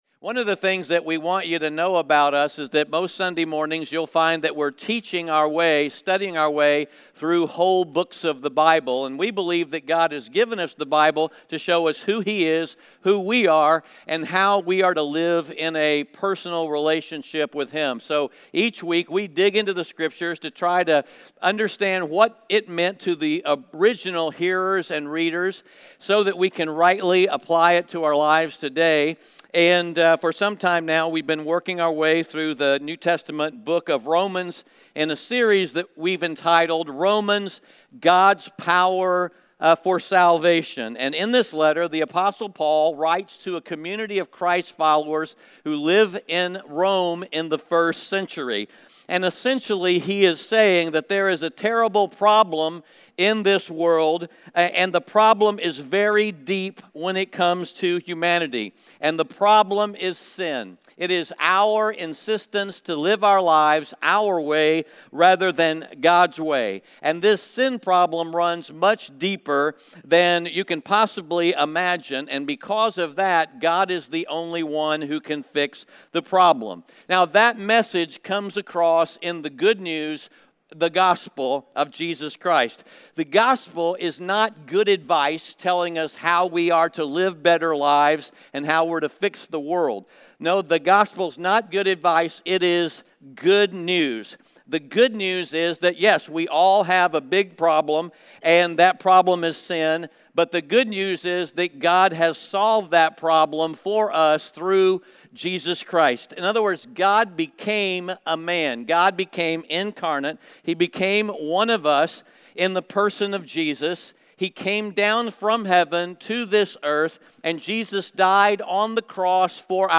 Romans 6:11-23 Audio Sermon Notes (PDF) Onscreen Notes Ask a Question *We are a church located in Greenville, South Carolina.